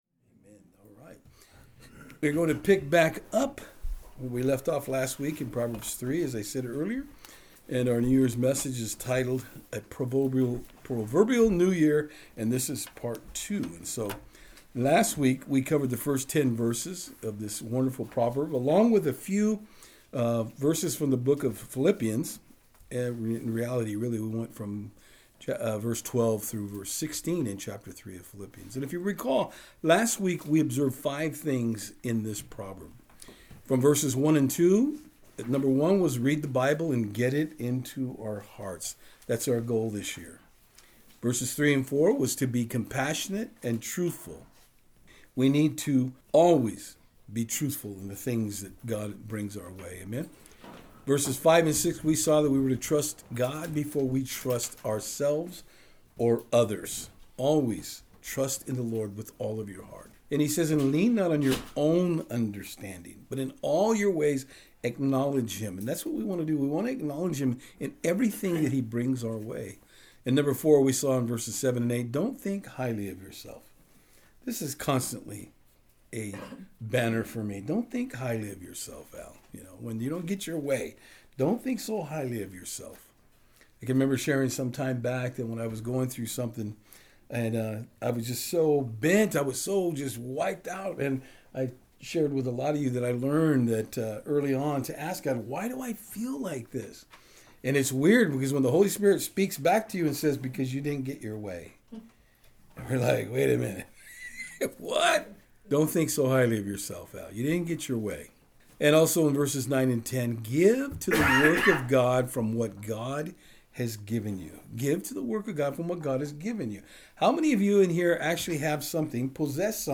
Series: Special Message